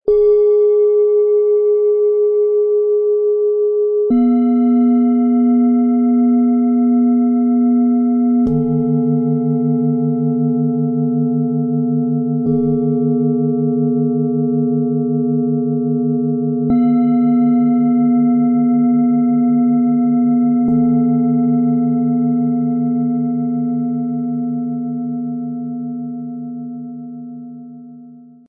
Klangschalen-Set für innere Klarheit, Balance & Transformation
Tiefster Ton: Saturn – Struktur und innere Stärke
Mittlerer Ton: Mond – Emotionale Balance und gelebte Bedürfnisse
Höchster Ton: Uranus – Klarheit und Transformation
Mit dem integrierten Sound-Player - Jetzt reinhören lässt sich der Originalklang dieses Sets direkt anhören.
Dem Set liegt ein kostenloser Standardklöppel bei, mit dem die Schalen bereits angenehm und harmonisch erklingen.Wer den Klang weiter verfeinern möchte, findet passendes Zubehör im Bereich "Dazu passt".
Bengalen Schale, Schwarz-Gold